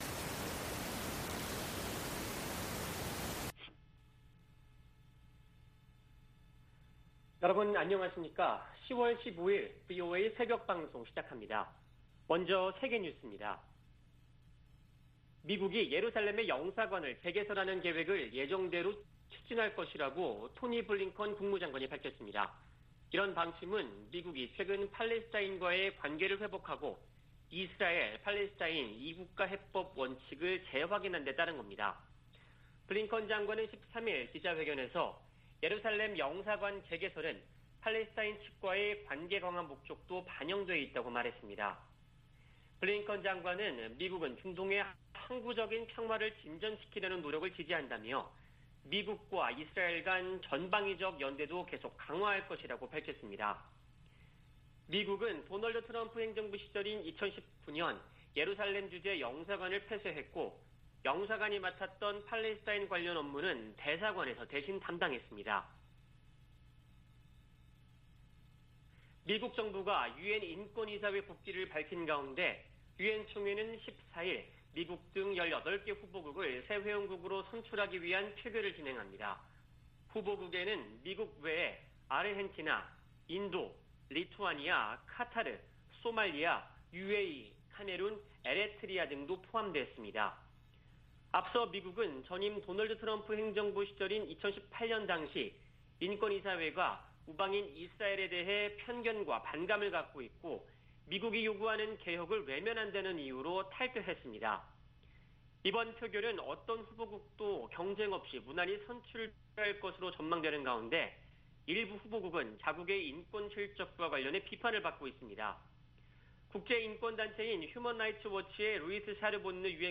VOA 한국어 '출발 뉴스 쇼', 2021년 10월 15일 방송입니다. 미 국가정보국장이 북한을 전통적인 위협으로 꼽았습니다. 미 공화당 의원들이 내년 11월 중간선거를 앞두고 조 바이든 대통령의 대외 정책을 비판하며 공세를 강화하고 있습니다. 한국 정부가 종전선언 문제를 거듭 제기하면서 미한 동맹의 북한 논의에서 주요 의제로 떠오르고 있습니다.